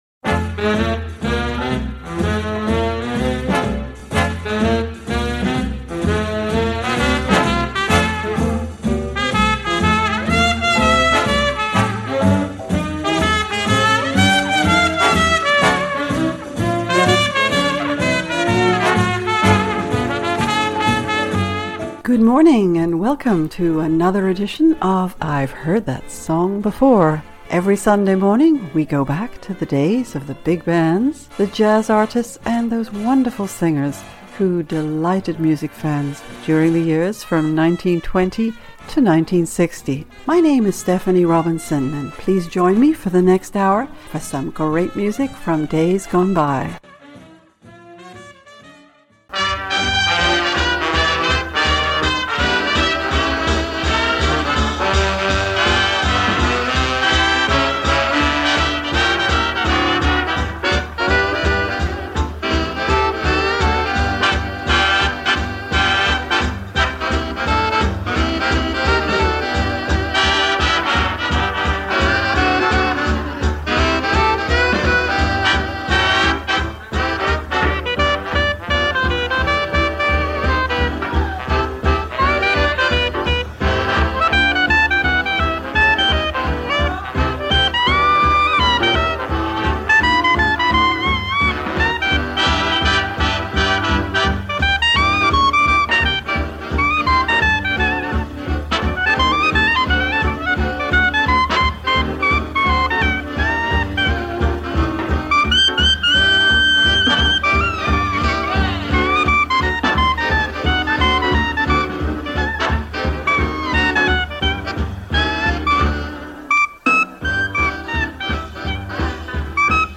smaller jazz groups